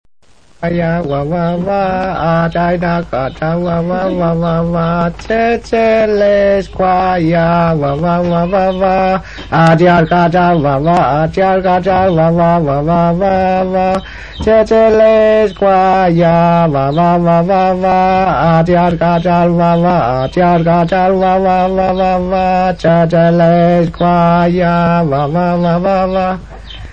la musique et les chants des Alacalufs